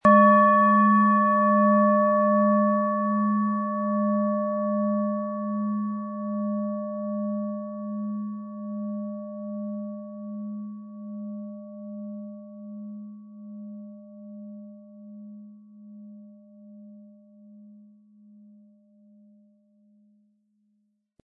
Planetenschale® Neue Ideen bekommen & Altes aufgeben mit Uranus, Ø 16 cm, 400-500 Gramm inkl. Klöppel
Planetenton 1
Unter dem Artikel-Bild finden Sie den Original-Klang dieser Schale im Audio-Player - Jetzt reinhören.
Lieferung inklusive passendem Klöppel, der gut zur Klangschale passt und diese sehr schön und wohlklingend ertönen lässt.